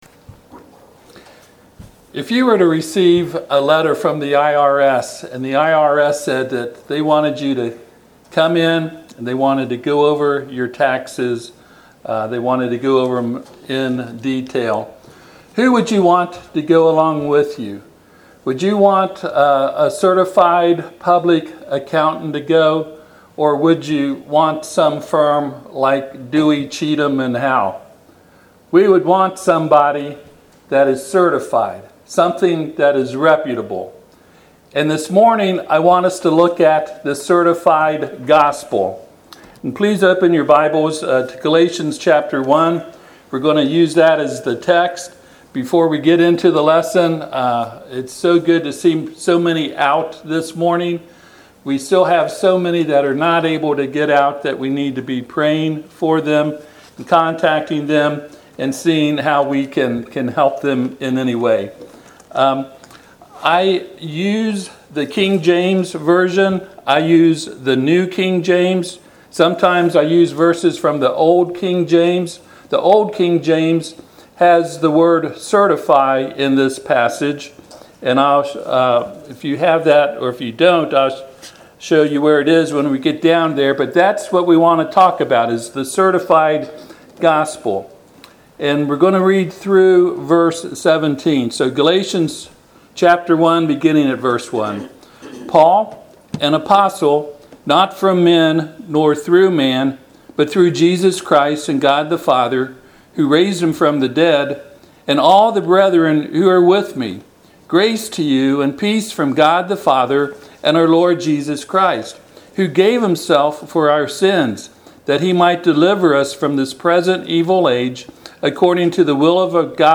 Passage: Galatians 1:1-17 Service Type: Sunday AM